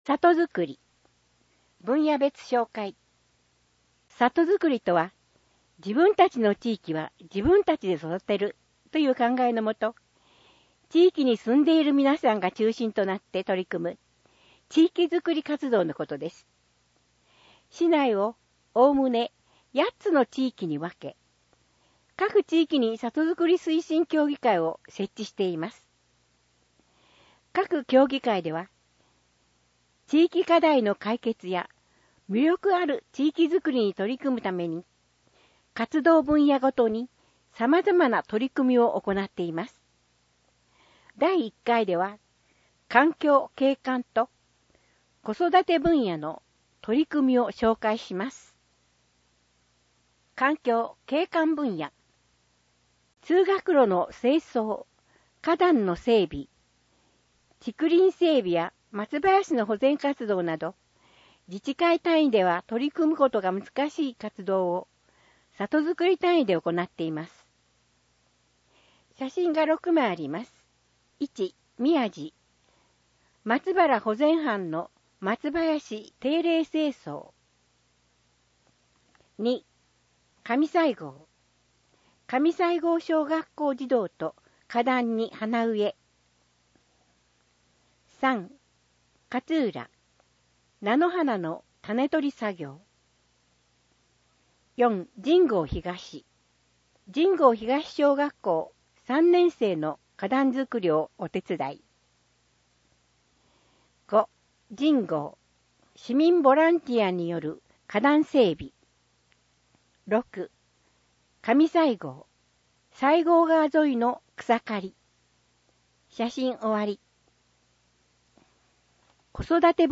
広報ふくつを音声で聞けます
音訳ボランティアふくつの皆さんが、毎号、広報ふくつを音訳してくれています。